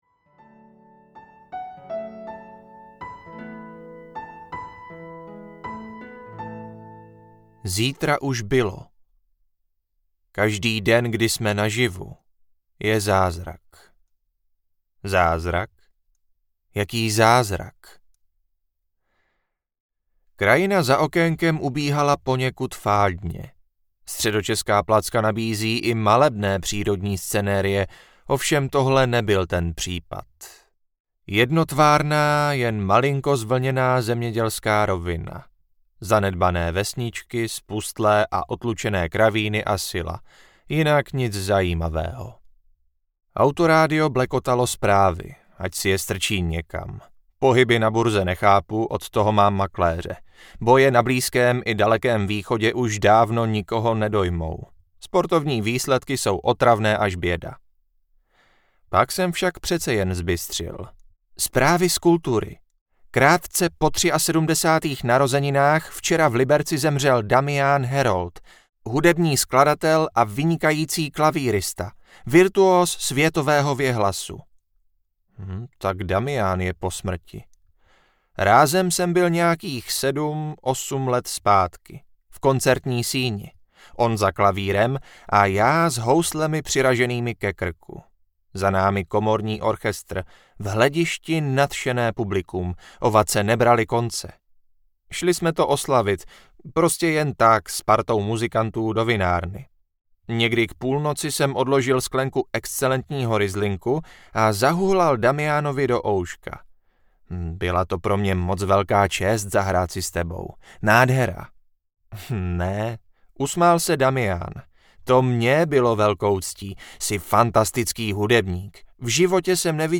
Zítra už bylo audiokniha
Ukázka z knihy
• InterpretLibor Böhm